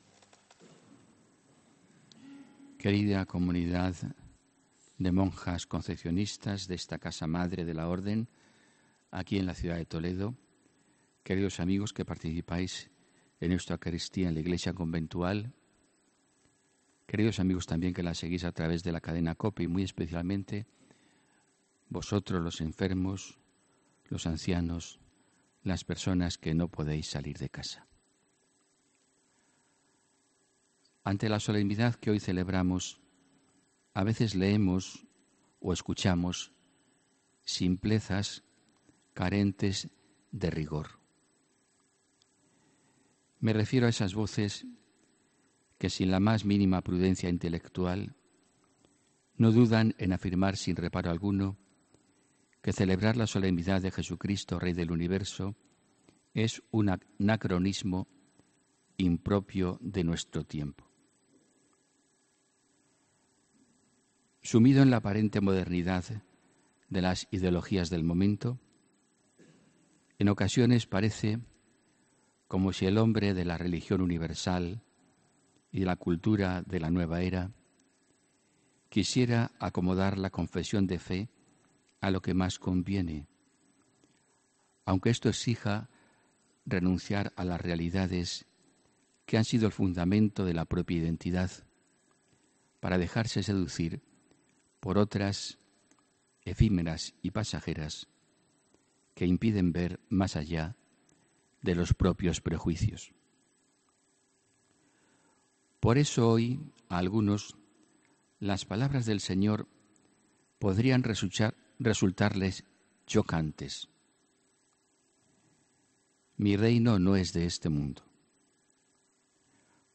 HOMILÍA 25 NOVIEMBRE 2018